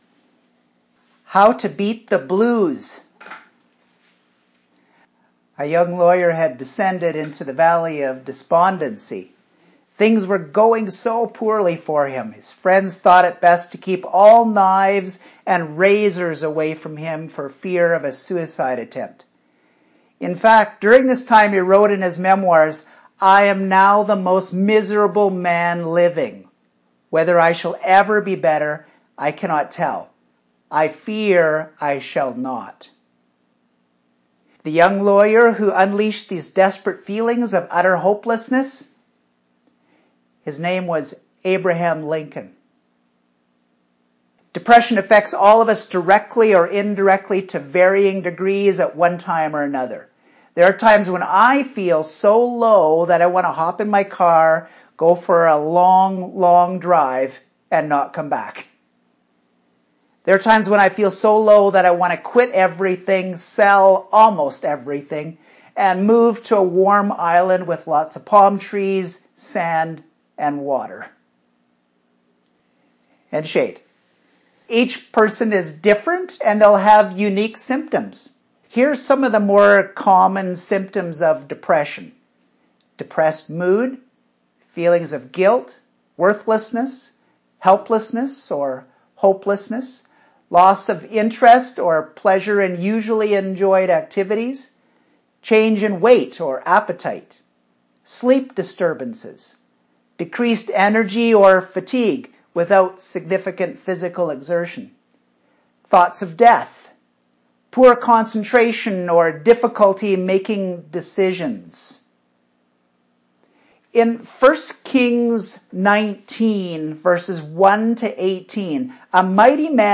Sermons | Project 412